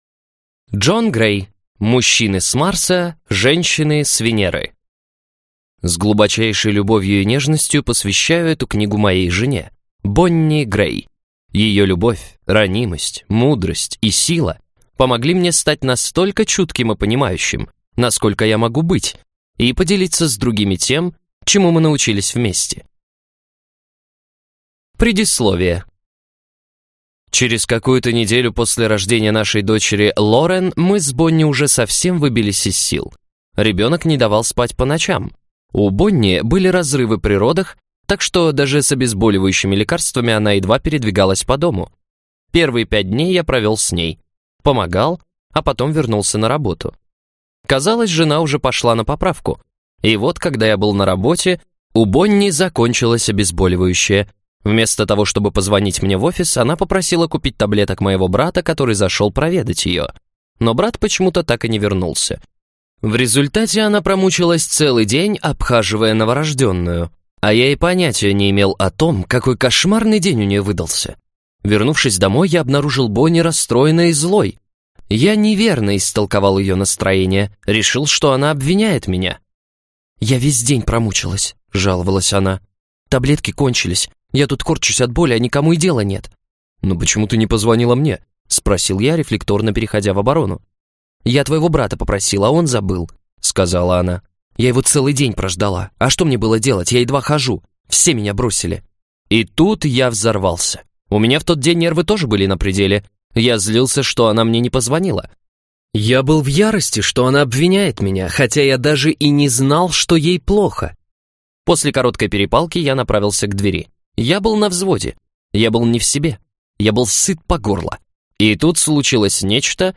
Аудиокнига Мужчины с Марса, женщины с Венеры | Библиотека аудиокниг